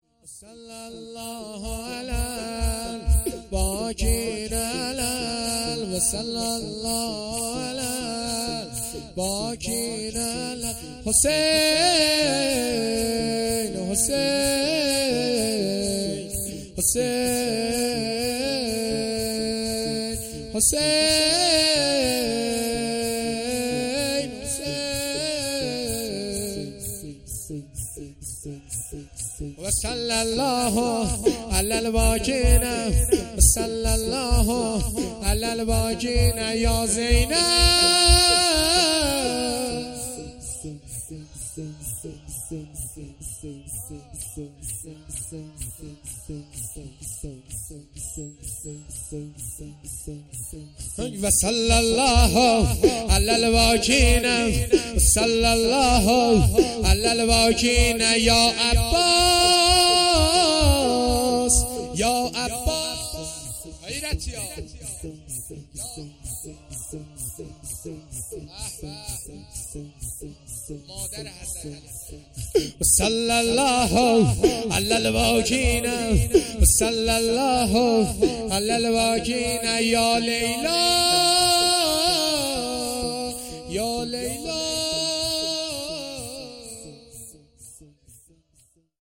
شور
محرم الحرام ۱۴۴۳